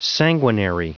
Prononciation du mot sanguinary en anglais (fichier audio)
Prononciation du mot : sanguinary